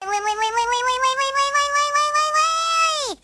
уведомление
детский голос